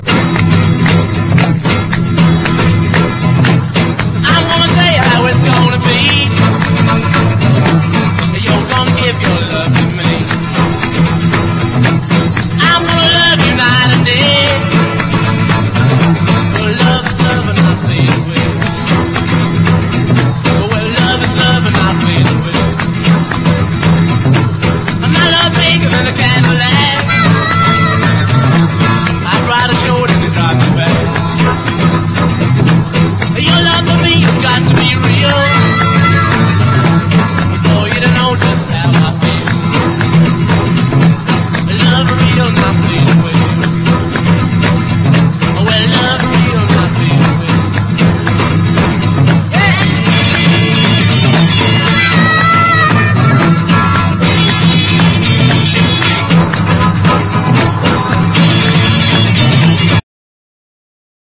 NEO-ACO/GUITAR POP
NEW WAVE
軽やかなフルートやゴージャスなストリングスも◎